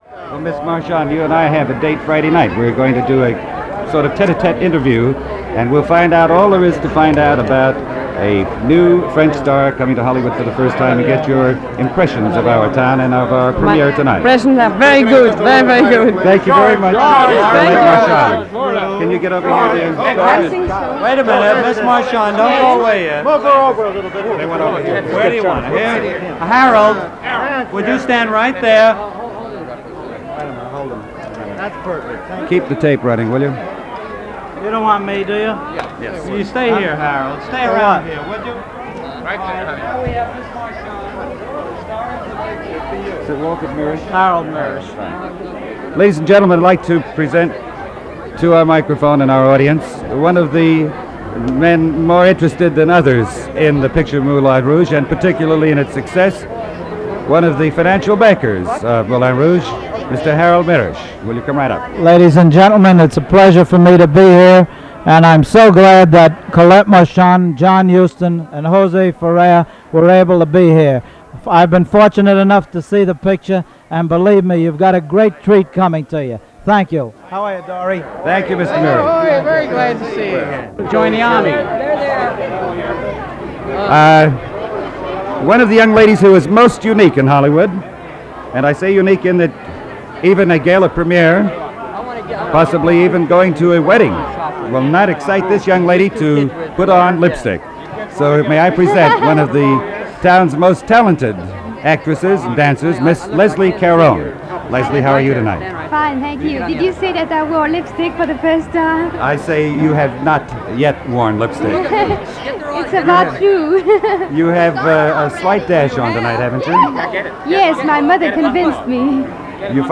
Here is a raw excerpt of the premier as it was recorded for KNX/CBS Radio